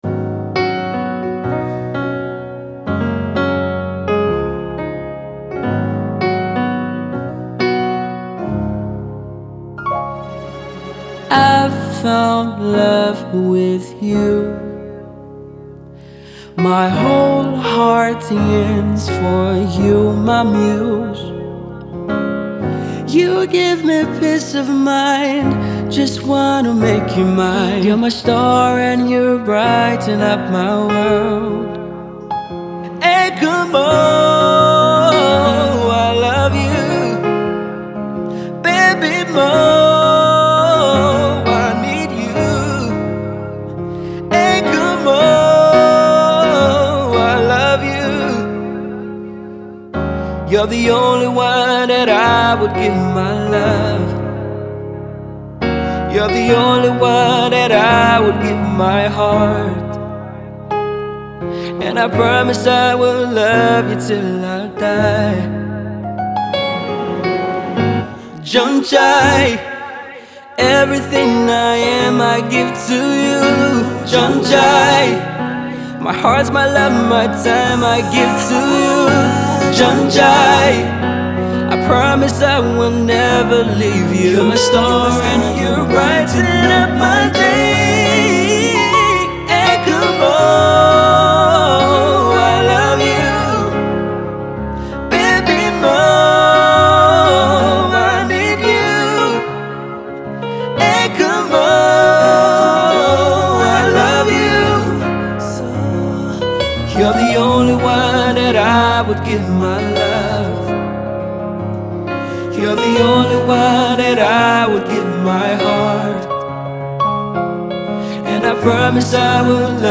is a ballad which tells a story of love.